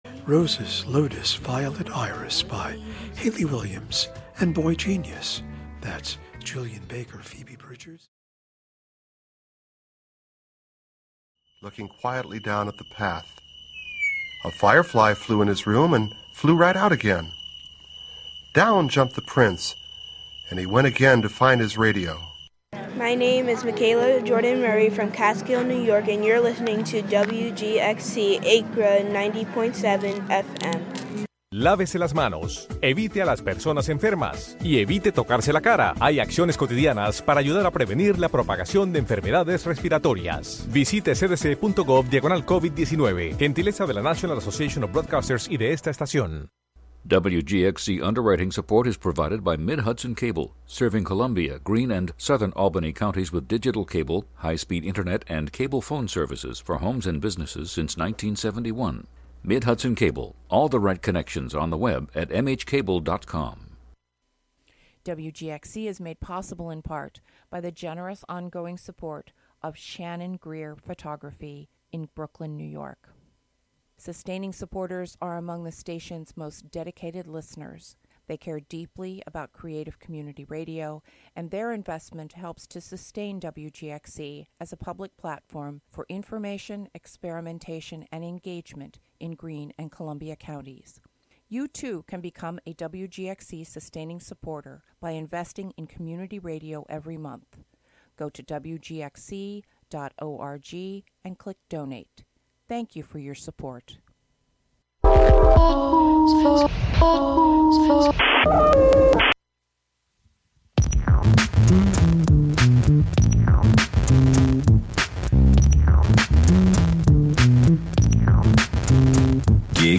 In this current era of indeterminacy, it's important to appreciate the history of live music and talk about it on the radio. Callers share their experience 'on the gig' and receive advice from the expert hosts.